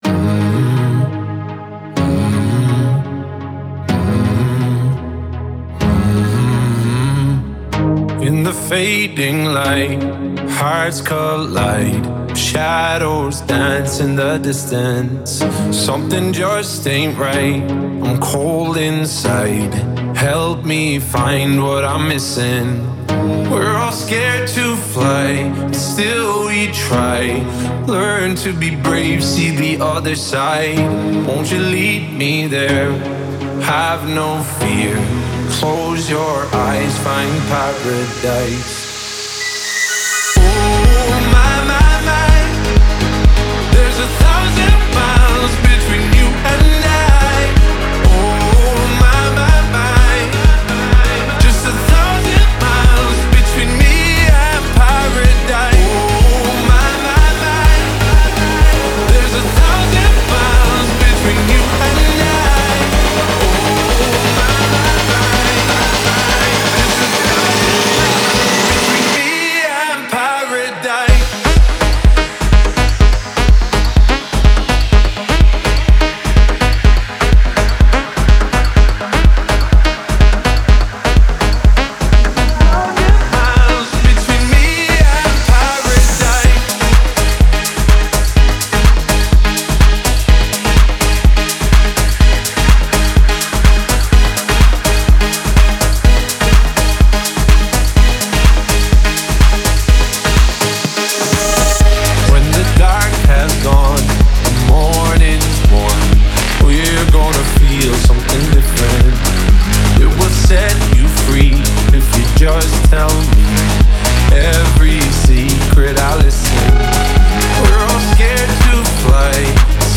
это яркий трек в жанре хаус
глубокие электронные ритмы
мощный вокал